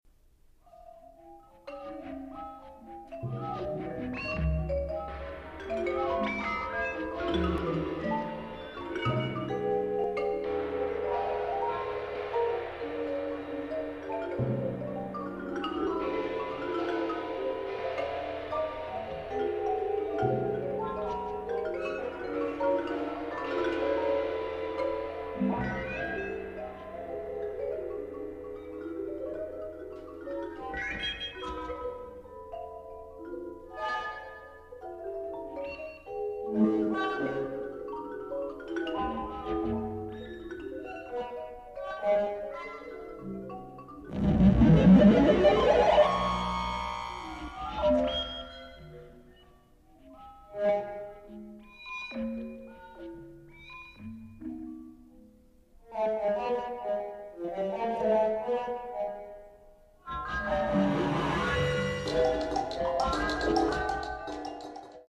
(seven percussionists and electronics)
Audio sample-Third Movement (.mp3)
The sounds are entirely analog and were created/modified using Moog equipment and Scully two/four channel tape recorders. Sections employing improvisation occur frequently and one entire movement (the Audio sample on this site) is based on improvisational techniques cued by a particular electronic sound.
The mallet instruments are treated individually and unconventionally in regard usual traditional performance techniques.